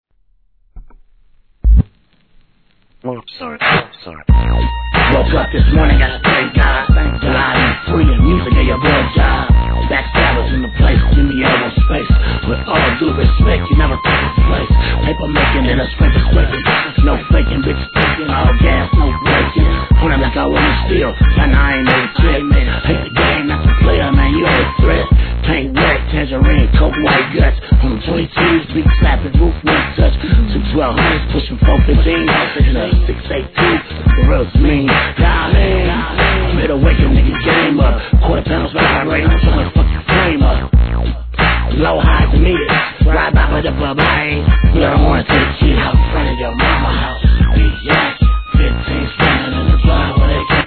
HIP HOP/R&B
ピ〜ヒャラシンセにレイドバックするユル〜イFUNKトラックで聴かせる濁声RAPがはまります!!